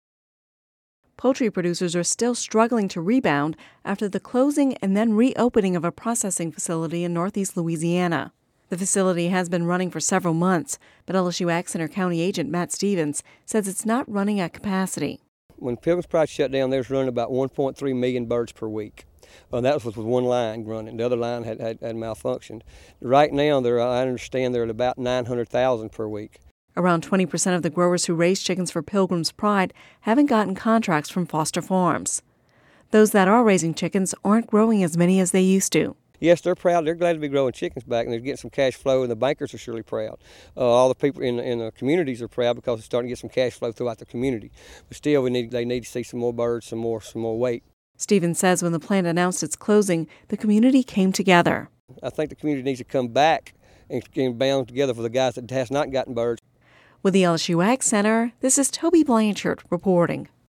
(Radio News 02/15/10) Poultry producers are still struggling to rebound after the closing and then reopening of a processing facility in Northeast Louisiana.